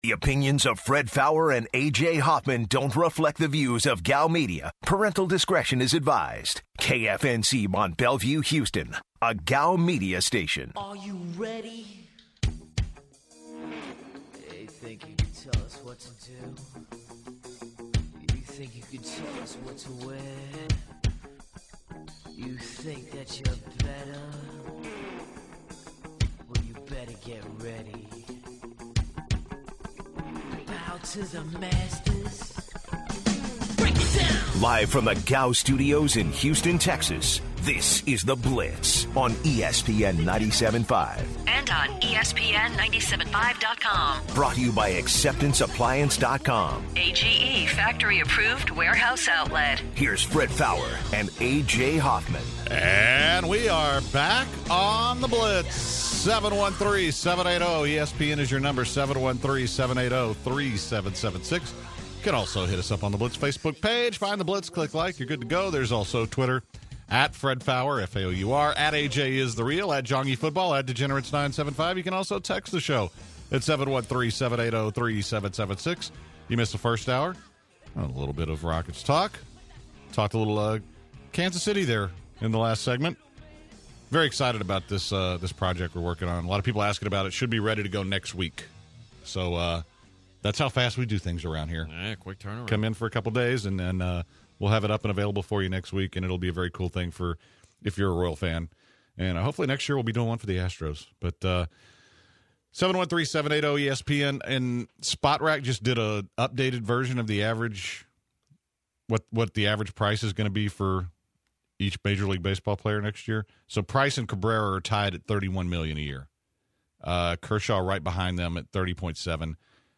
To open the second hour, the guys take calls from fellow blitzers regarding: the rockets ball distribution and how baseball contracts are structured. Then, the guys discuss how they predict the CF committee with rank the top four.